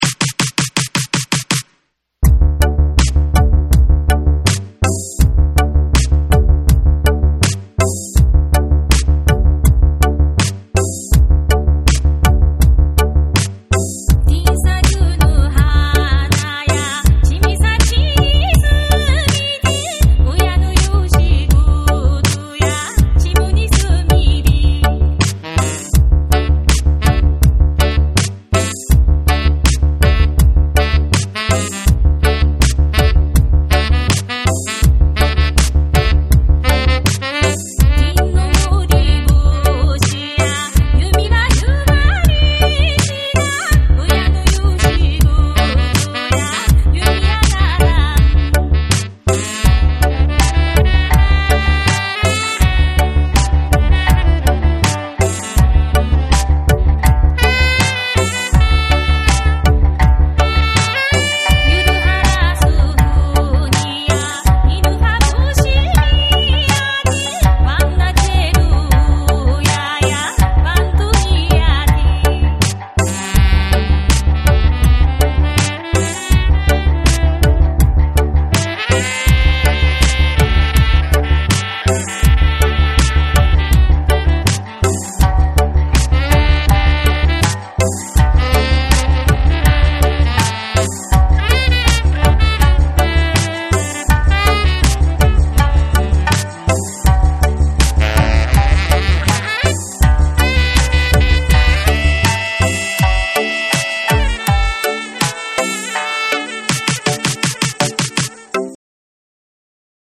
JAPANESE / REGGAE & DUB